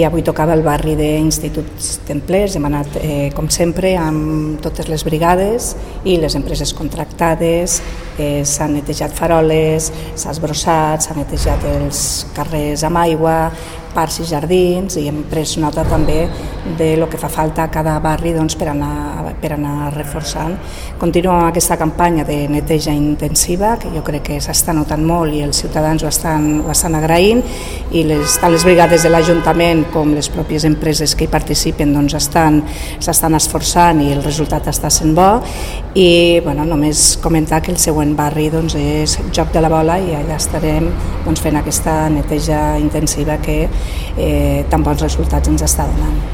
Iglesias ha comentat que el resultat d’aquesta campanya ja s’està notant i que els veïns i veïnes estan valorant l’esforç que s’hi fa. La tinent d’alcalde ha agraït l’esforç de l’equip d’operaris, tant municipals com de les empreses privades, i la coordinació que s’està teixint per dur a terme aquest Barri a Barri. Tall de veu B. Iglesias La campanya, que va començar a principis de juliol a la Bordeta, ja ha actuat a diversos barris.